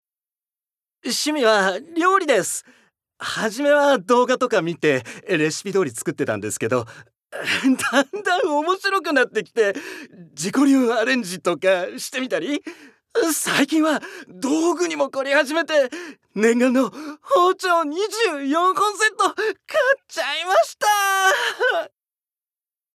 Voice Sample
ボイスサンプル
セリフ４